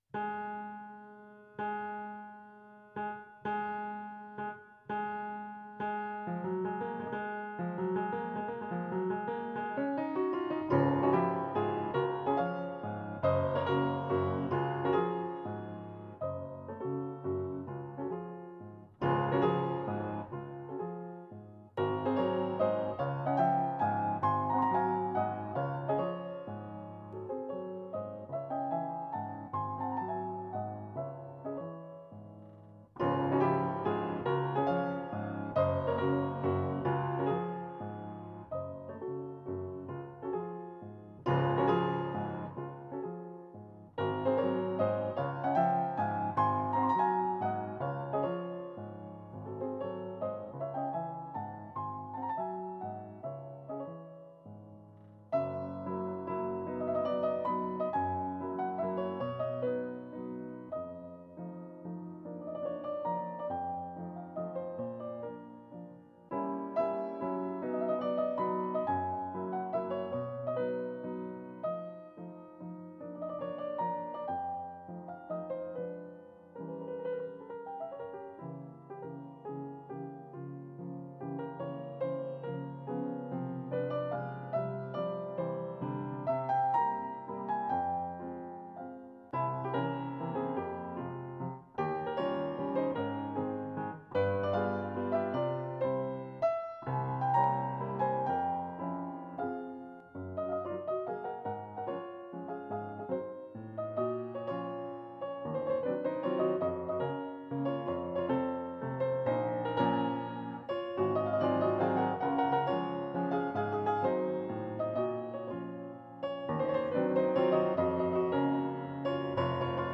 Piano  (View more Advanced Piano Music)
Classical (View more Classical Piano Music)